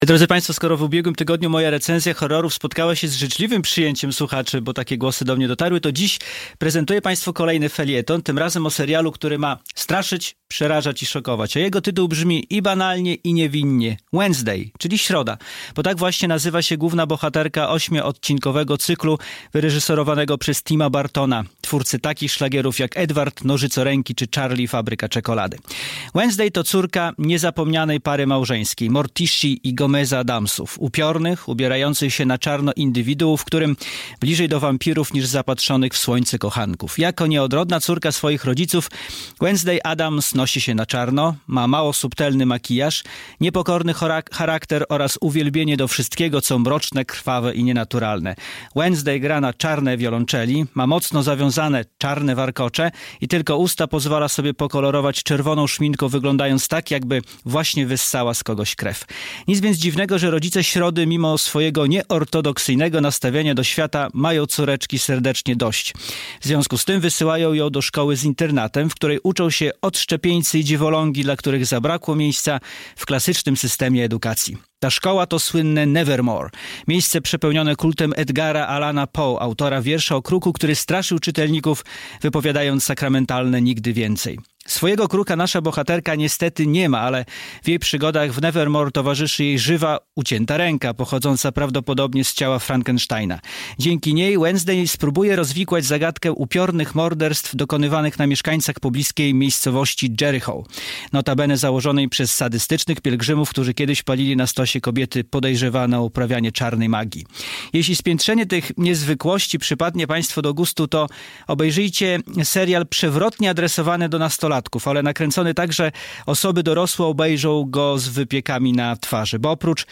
Dziś prezentuję państwu kolejny felieton – tym razem o serialu, który ma straszyć, przerażać i szokować.